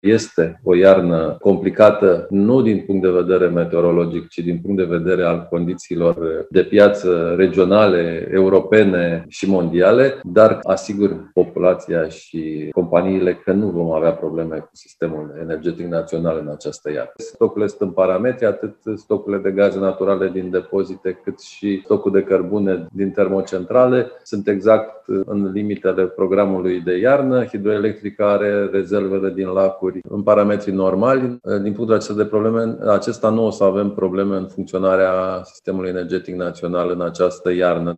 Nu vom avea probleme cu sistemul energetic național în această iarnă, ne asigură ministrul Energiei, Virgil Popescu. La o dezbatere organizată de Focus Energetic, ministrul a spus că ne așteaptă o iarnă complicată din cauza turbulențelor de pe piața externă, dar că avem suficiente stocuri de gaze, cărbune și energie electrică pentru a trece cu bine de sezonul rece.